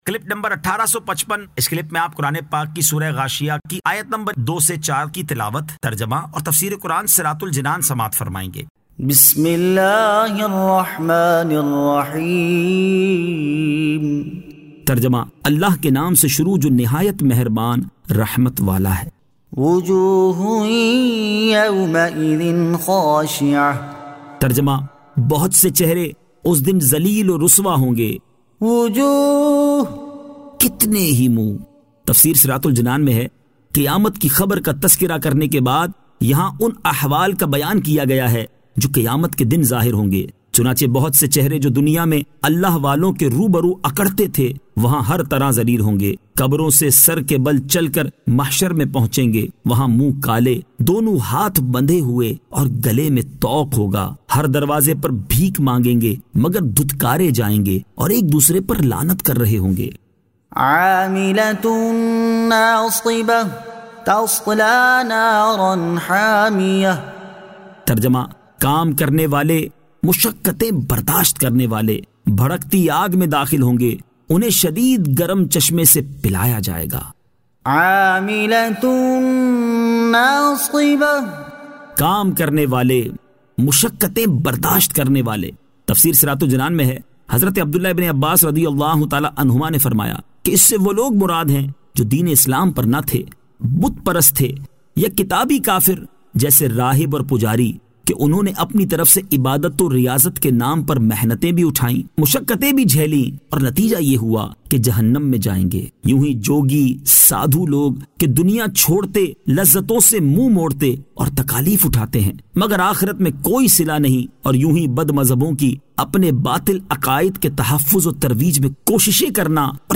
Surah Al-Ghashiyah 02 To 04 Tilawat , Tarjama , Tafseer